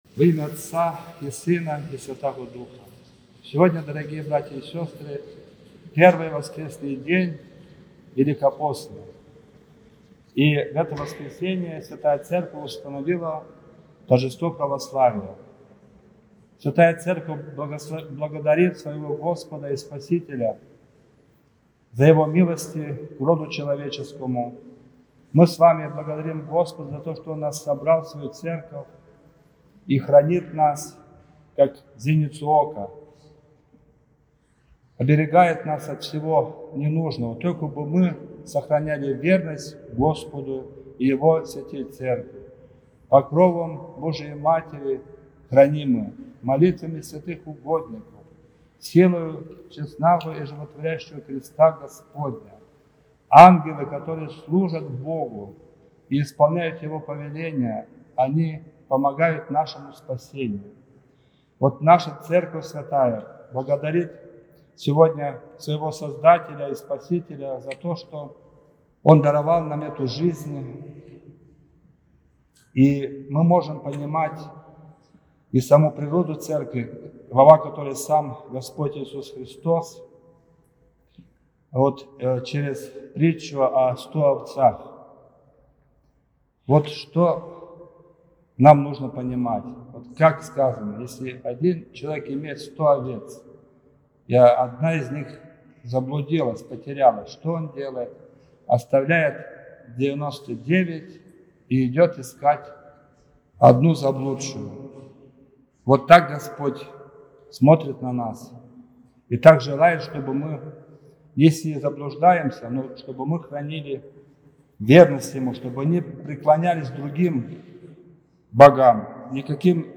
Проповедь-Неделя-1-я-Великого-поста.-Торжество-Православия.mp3